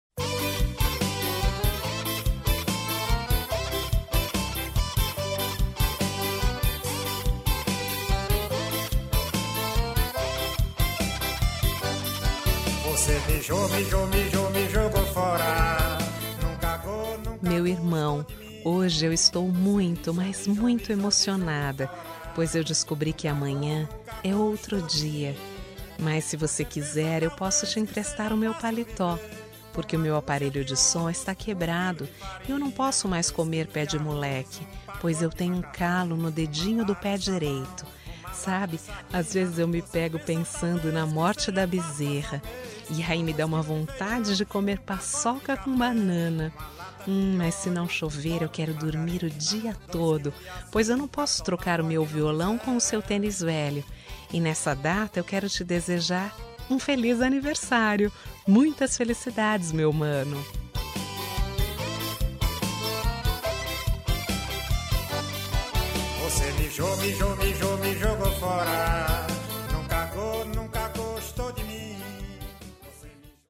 Aniversário de Humor – Voz Feminina – Cód: 200110